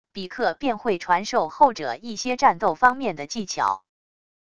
比克便会传授后者一些战斗方面的技巧wav音频生成系统WAV Audio Player